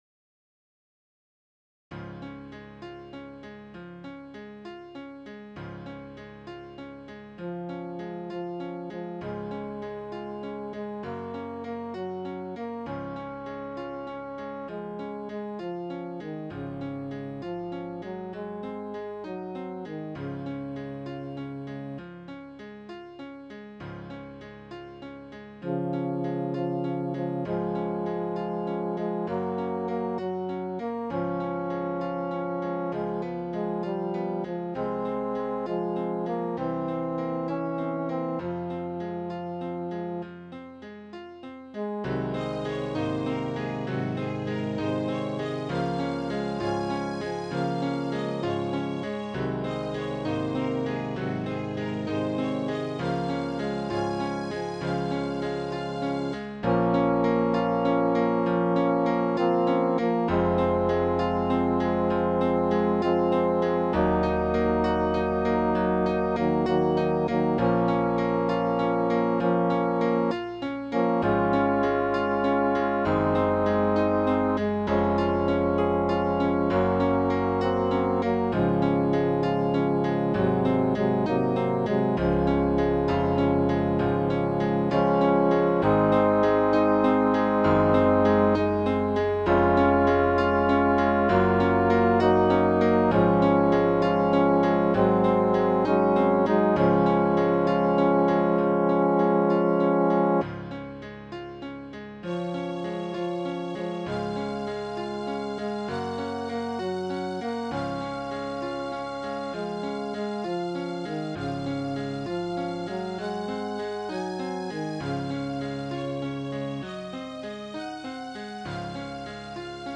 Choir with Soloist or Optional Soloist